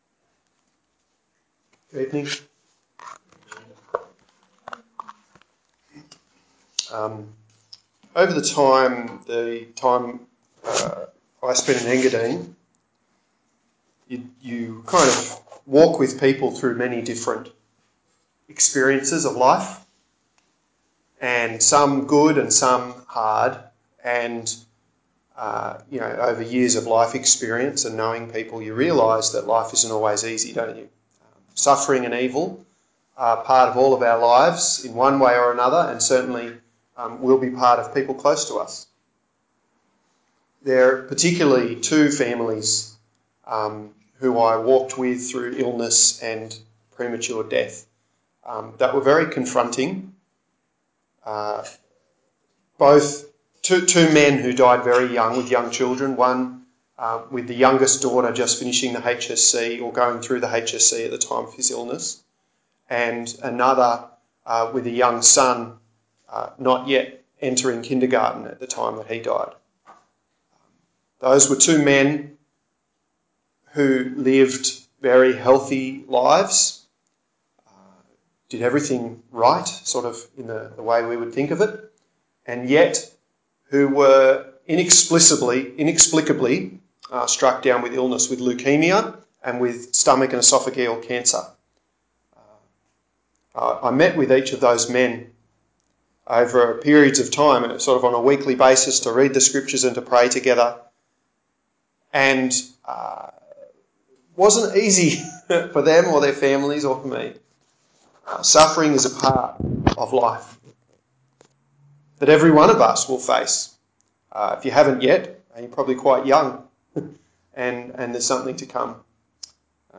14/05/2017 Suffering and Evil Preacher
Psalm 22:1-11 Service Type: Wilton « Suffering and Evil How can a loving God send people to Hell?